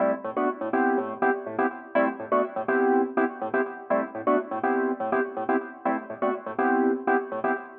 08 ElPiano PT 1-4.wav